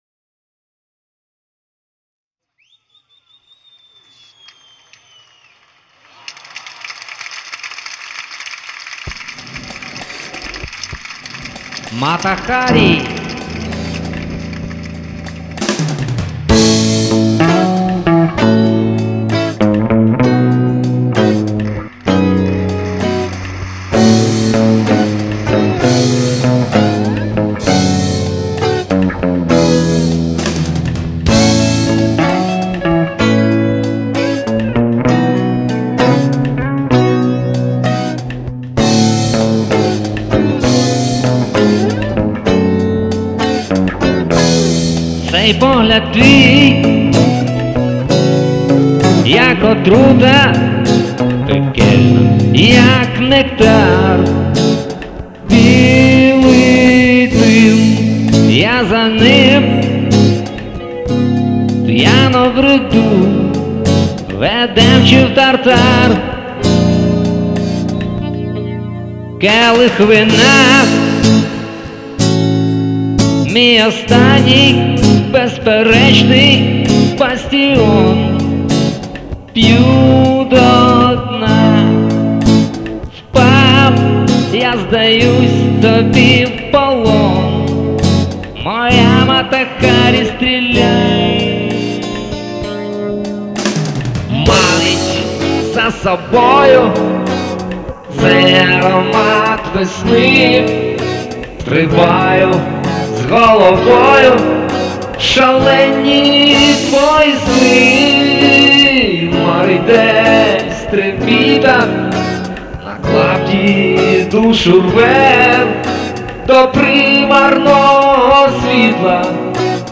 Мата Харі (блюз)